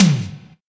TOM04.wav